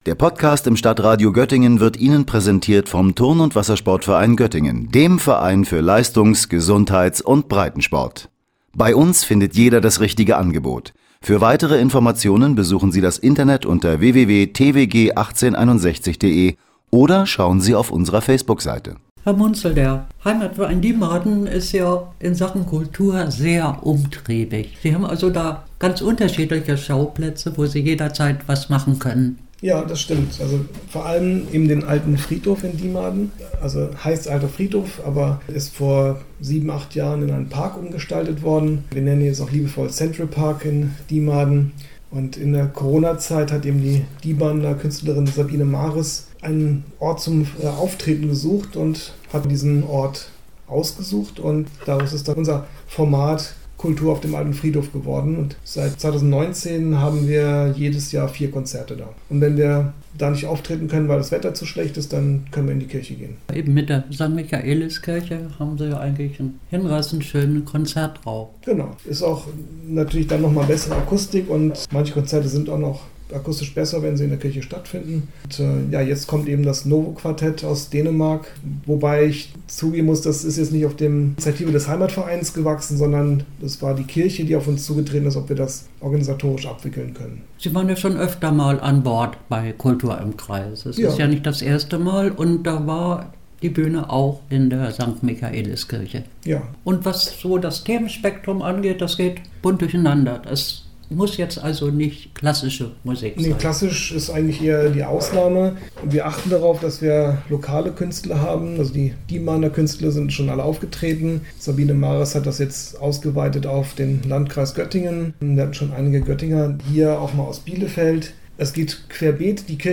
Festivalauftakt mit dem Novo Quartet“ über das Repertoire an kulturellen Streifzügen in Diemarden gesprochen.